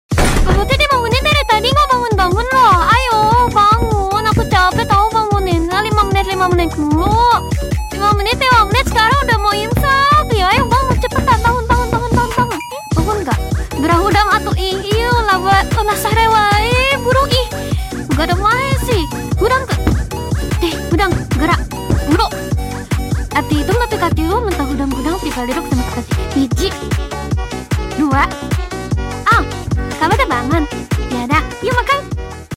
voice: furina